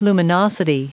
Prononciation audio / Fichier audio de LUMINOSITY en anglais
Prononciation du mot luminosity en anglais (fichier audio)